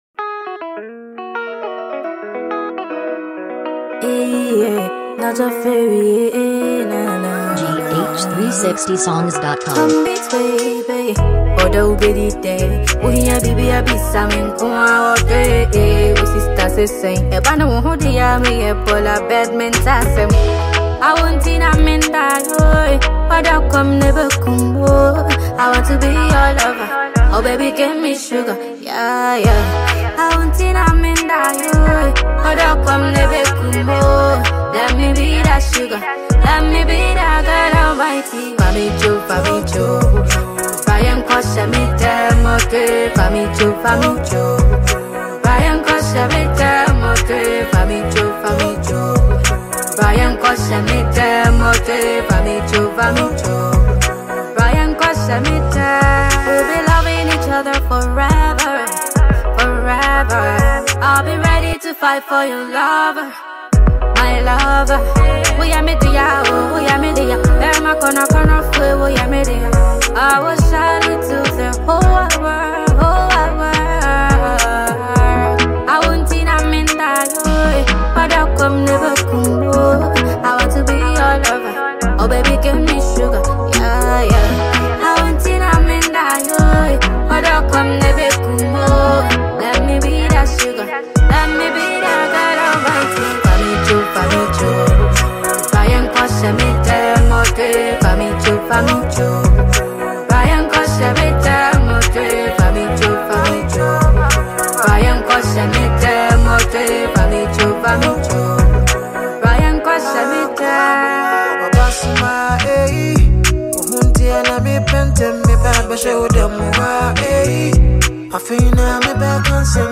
Ghana Music
Ghanaian talented songstress
highlife